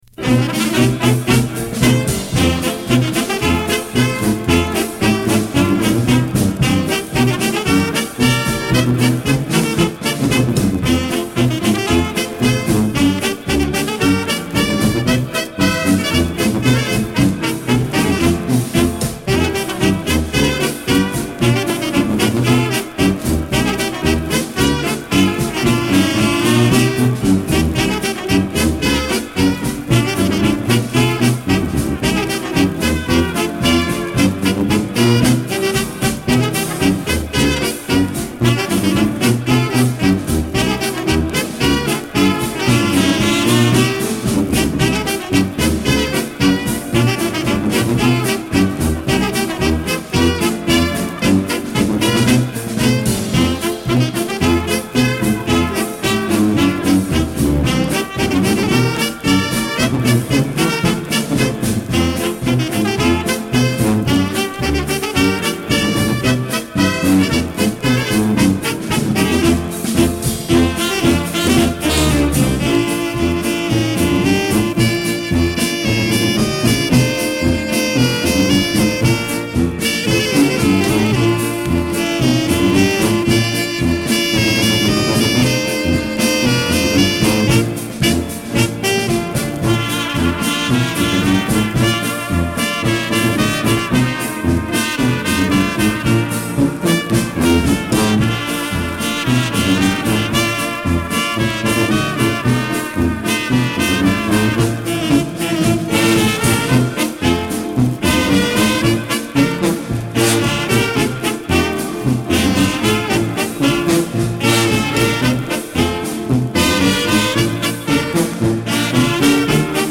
Commentary 10.